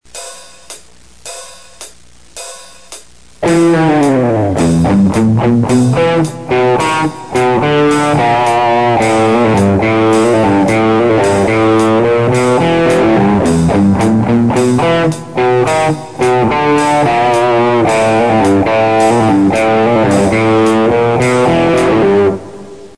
Этот ми-минорный риф играется на верхнем порожке (в 1-й позиции), и, как вы сами можете убедиться из ПРИМЕРА 1, он не так уж и замысловат.
Это длинное начальное глиссандо вниз, короткие паузы глушением струн ладонью, поистине дьявольское пальцевое вибрато на си-бемоль в конце первого такта и восходяще-нисходящее глиссандо во втором такте.